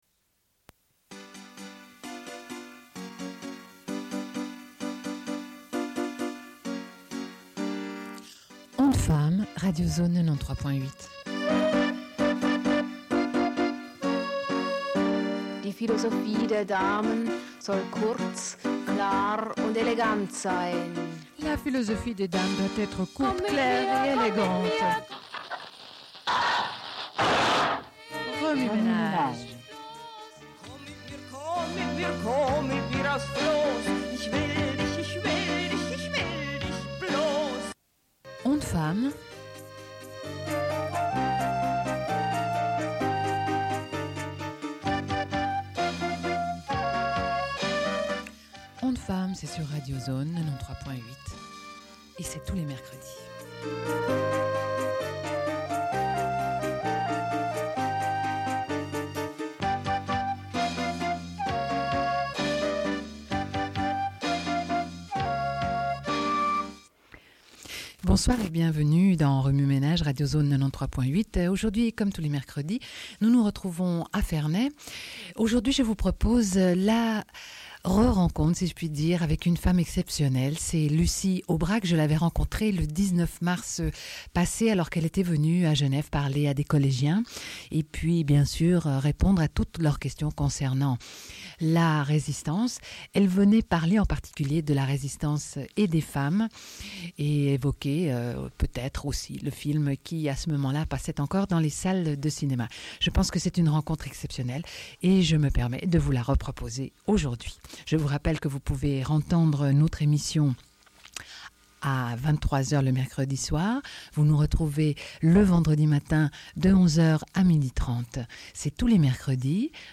Sommaire de l'émission : diffusion d'une rencontre avec Lucie Aubrac à l'occasion d'une conférence au Collège de Staël sur les femmes et la résistance en France.
Radio